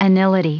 Prononciation du mot anility en anglais (fichier audio)
Prononciation du mot : anility